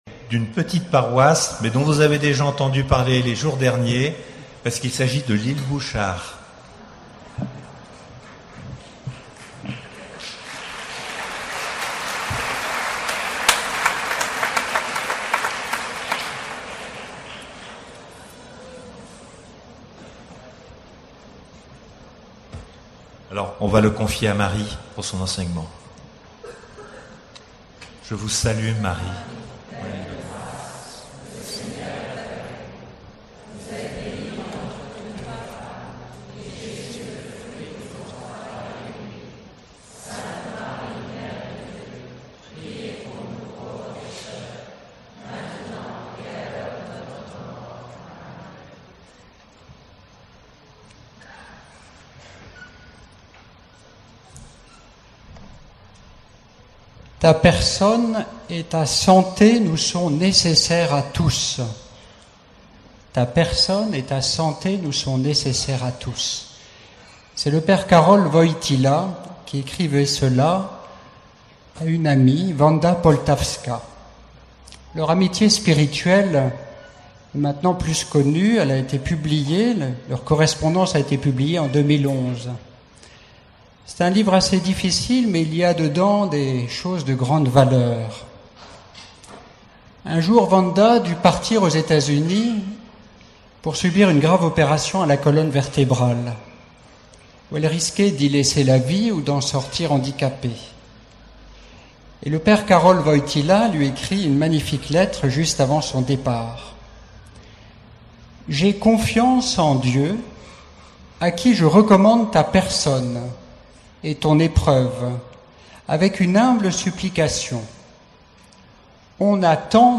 Session famille adoration (22 au 26 juillet 2012) Grand Enseignement Enregistr� le 25 juillet 2012.